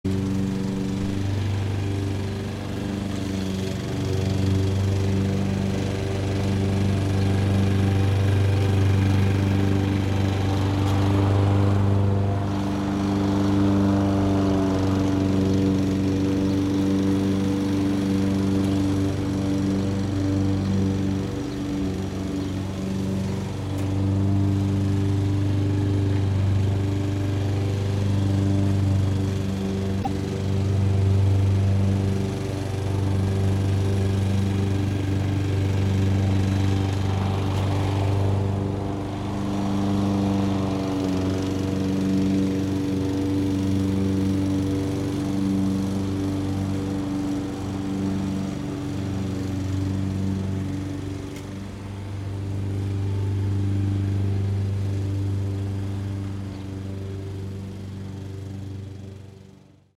پکیج افکت صوتی گاراژ و فضای باز
A collection of sounds related to the outdoor areas of a home. Sounds include: garage doors, air vents, garden tools, patio furniture, shutters, snow blowers and more.
Blastwave.FX.Basement.Garage.and.Outdoors.mp3